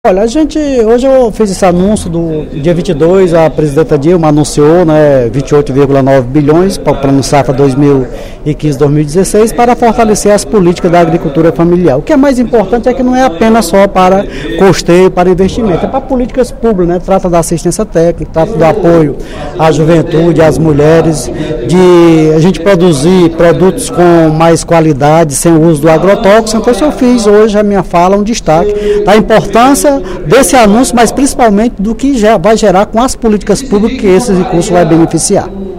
O deputado Moisés Braz (PT) ressaltou, no primeiro expediente da sessão plenária desta quinta-feira (25/06), o anúncio feito pelo Governo Federal, na última segunda-feira (22/06), sobre a liberação de R$ 28,9 bilhões para o Plano Safra da Agricultura Familiar 2015-2016.